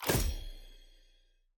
sfx-loot-reroll-button-click.ogg